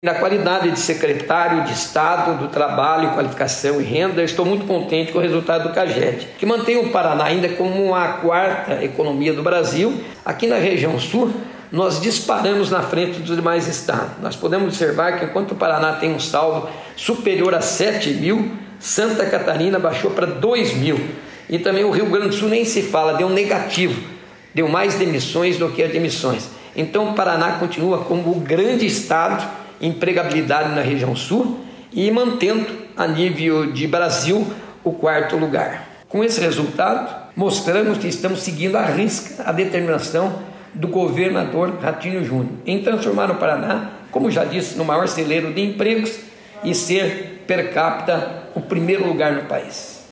Sonora do secretário do Trabalho, Qualificação e Renda, Mauro Moraes, sobre os dados de julho divulgados pelo Caged